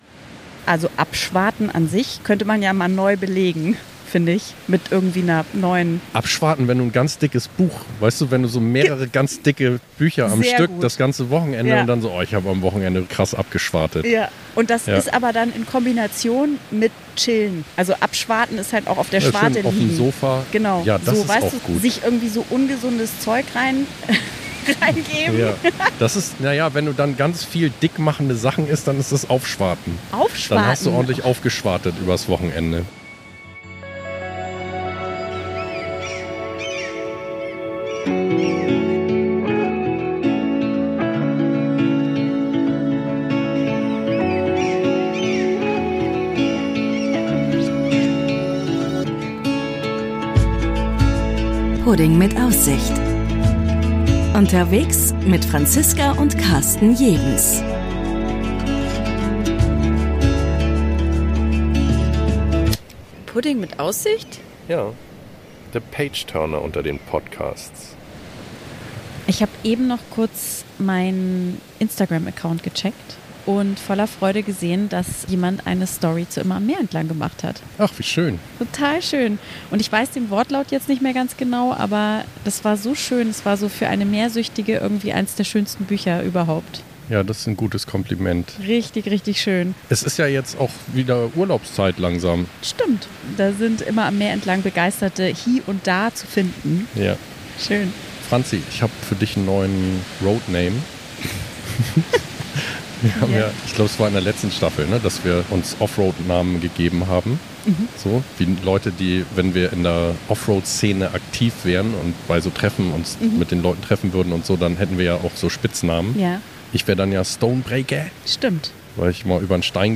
Auf einer verlassenen Verladerampe hoch oben auf den Klippen haben wir unsere Mikros aufgestellt, um Euch von unseren letzten Reiseabenteuern zu berichten.